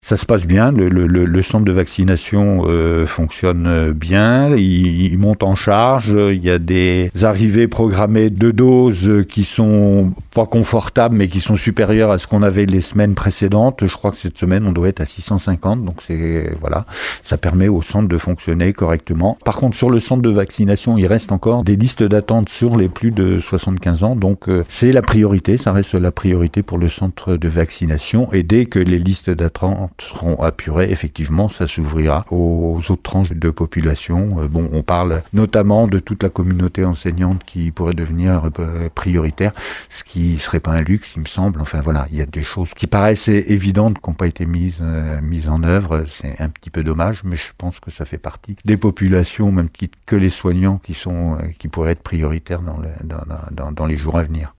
En Charente-Maritime, une montée en puissance est attendue courant avril avec un doublement du nombre de doses, passant de 9000 à 18000 par semaine. A Surgères par exemple, le rythme est de plus en plus soutenu au centre de vaccination comme le souligne le président de la Communauté de communes Aunis Sud Jean Gorioux :